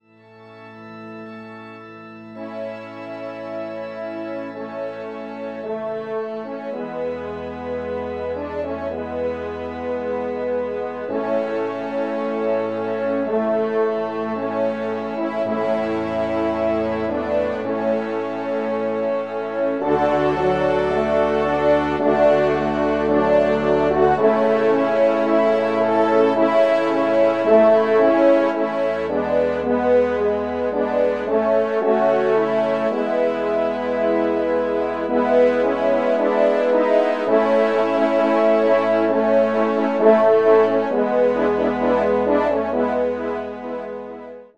Genre : Divertissement pour Trompes ou Cors et Orgue
ENSEMBLE     (44 sec.)  mp3 Musescore 4